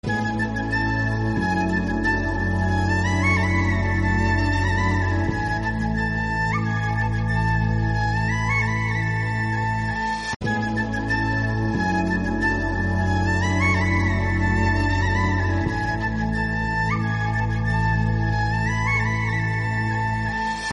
Ringtones Category: Top Ringtones